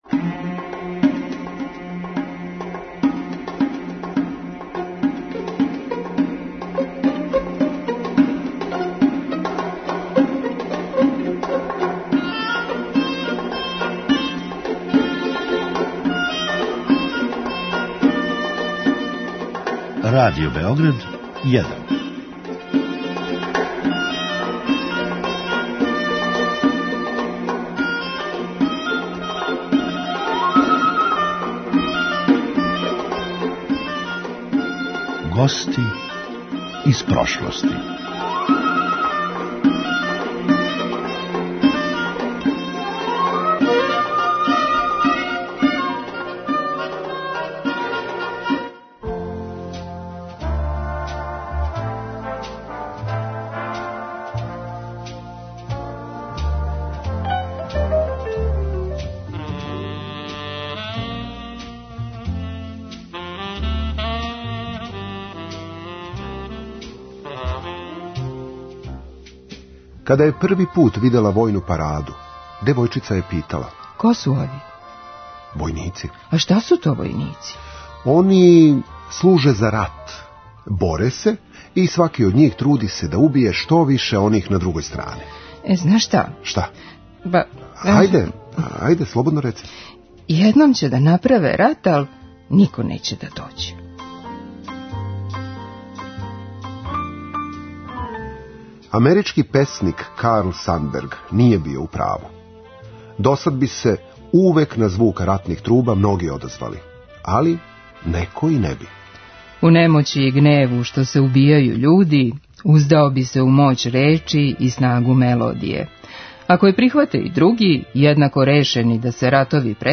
Извор: Радио Београд 1